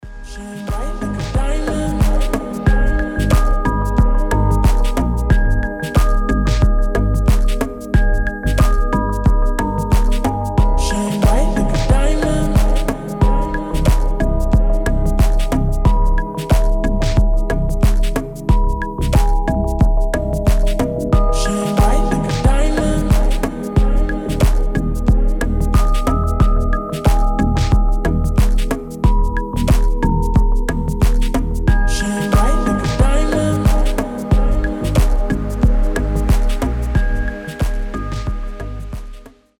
deep house
спокойные
chillout
медленные
расслабляющие
ремиксы